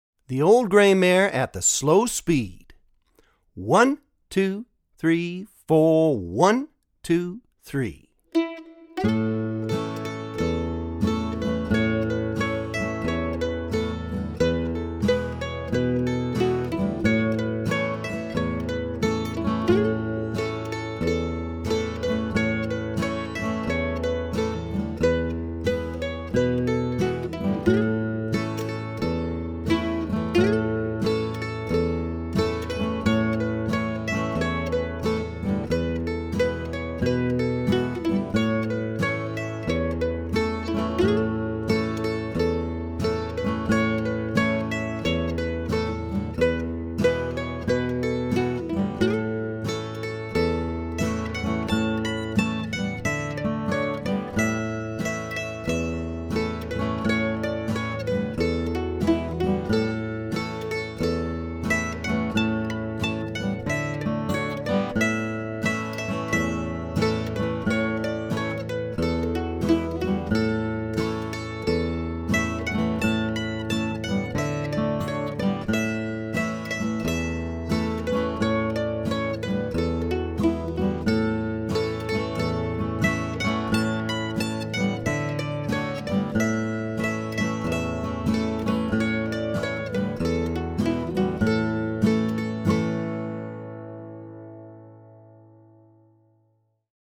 DIGITAL SHEET MUSIC - MANDOLIN SOLO
Traditional Mandolin Solo
Online Audio (both slow and regular speed)